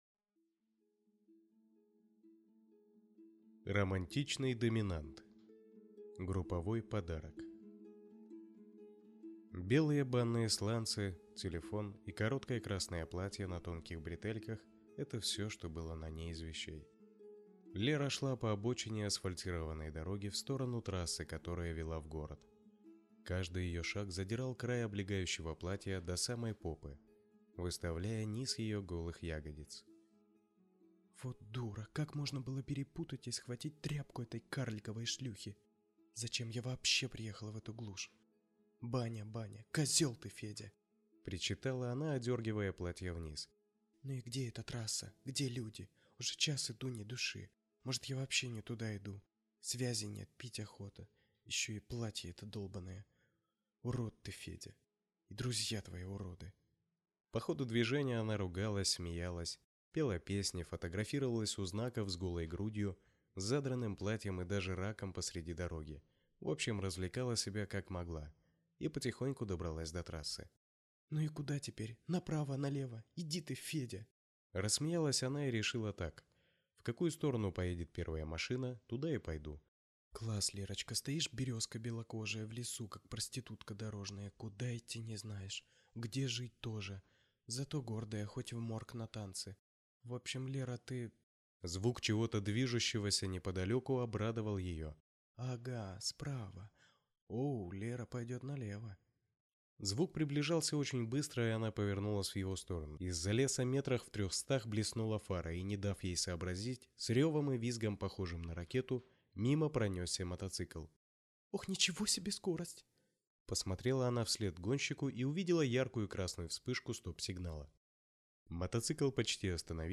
Аудиокнига Групповой подарок | Библиотека аудиокниг